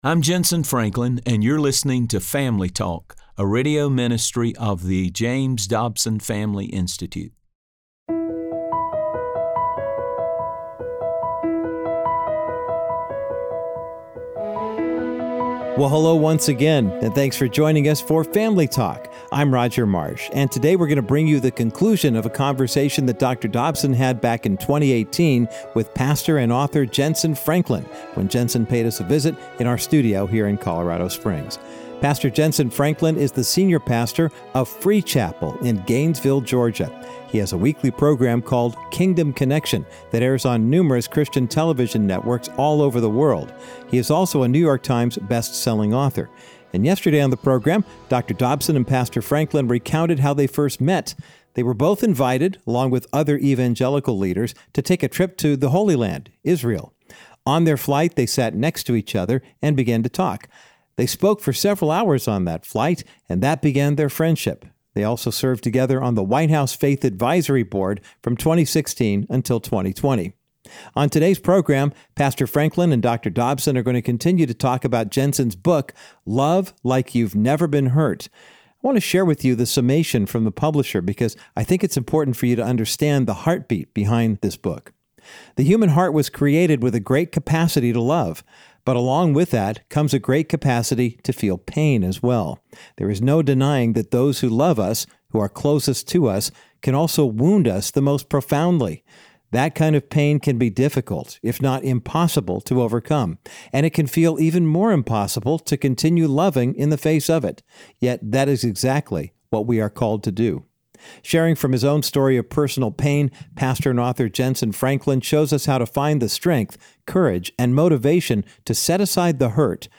On today’s edition of Family Talk, Dr. James Dobson concludes his encouraging conversation with Pastor Franklin about some of his life experiences using unconditional love.